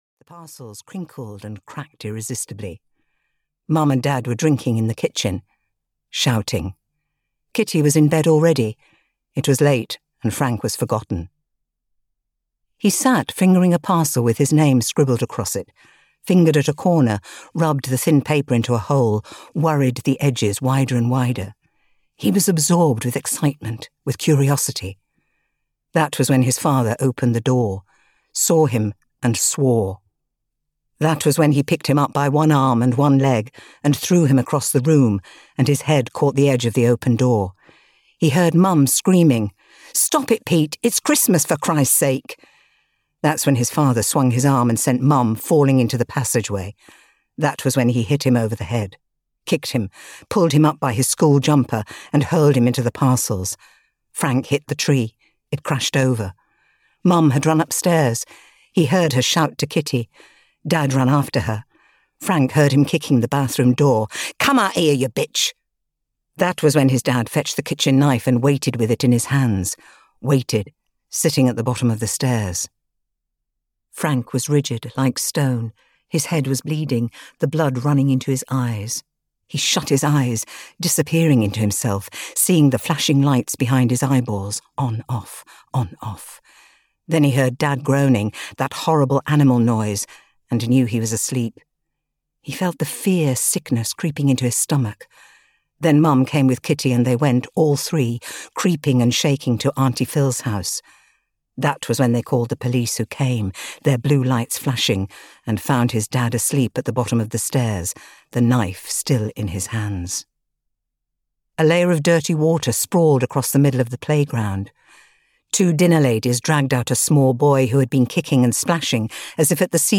Arms Around Frank Richardson (EN) audiokniha
Ukázka z knihy